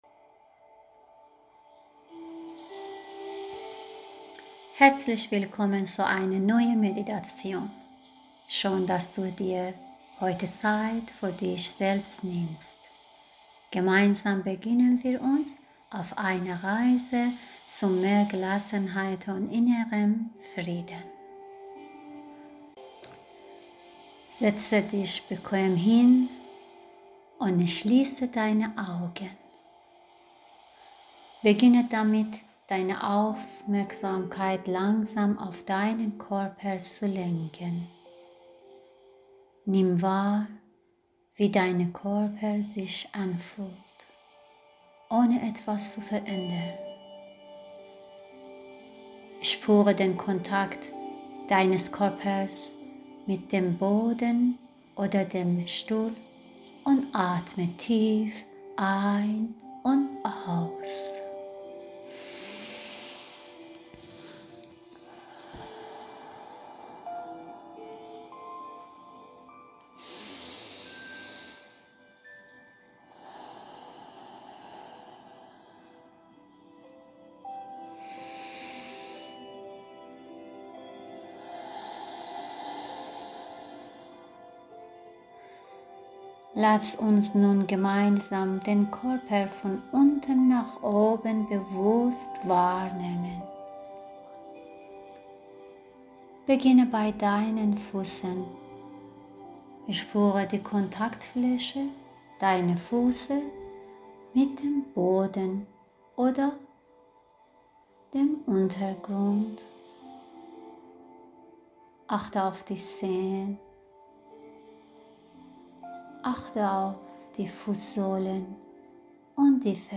Körperwahrnehmung Meditation